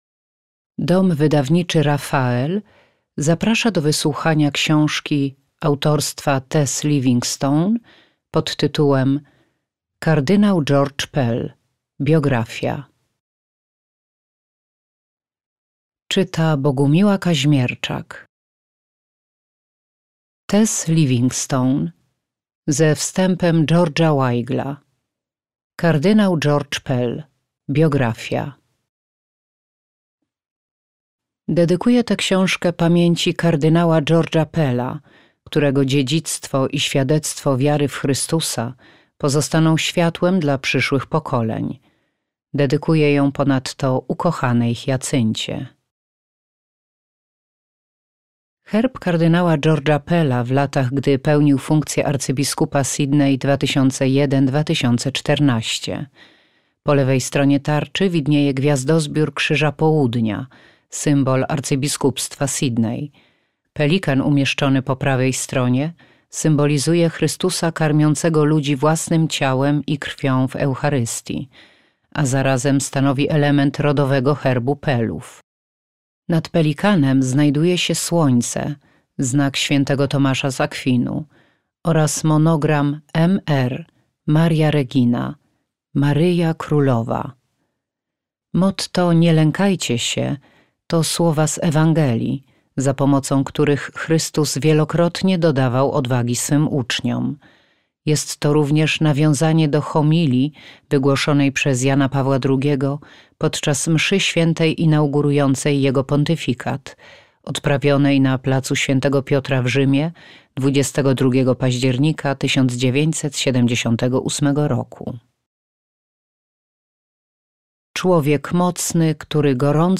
Kardynał George Pell. Biografia - Livingstone Tess - audiobook